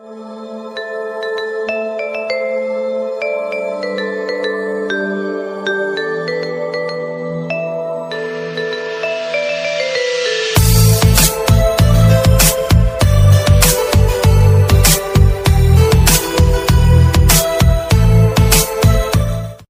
Category: Instrumental Ringtones